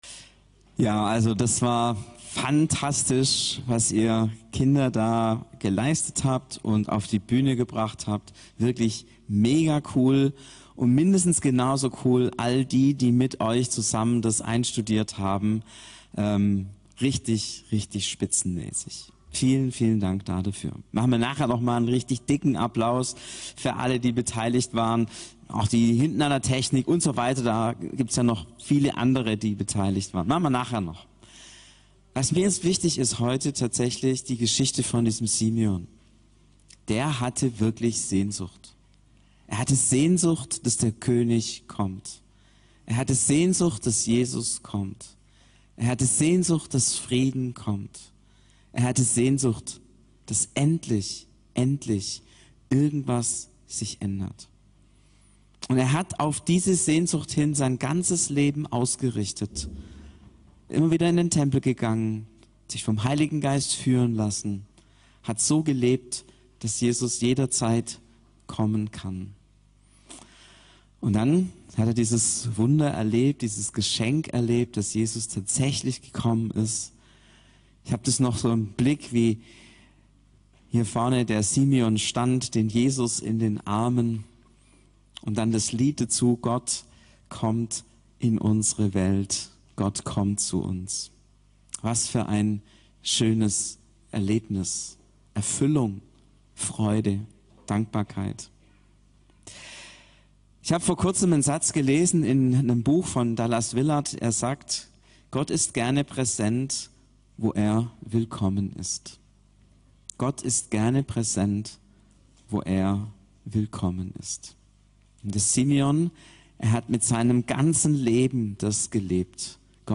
Hier erscheinen meist wöchentlich die Predigten aus dem Sonntags-Gottesdienst des CVJM Stuttgart